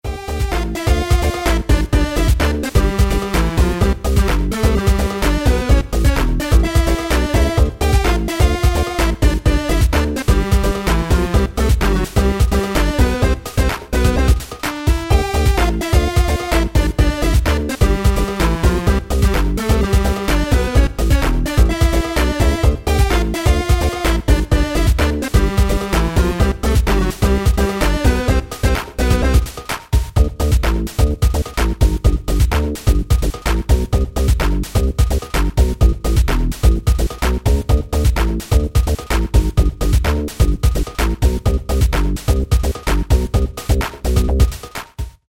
2015 » Без Слов » Из Игр » Электроника Скачать припев